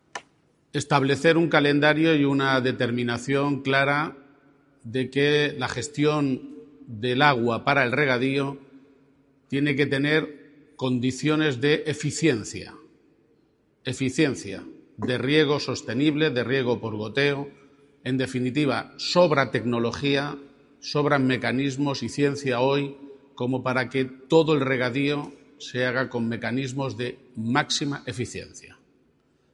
GARCÍA-PAGE: INTERVENCIÓN ARLEM_USO EFICIENTE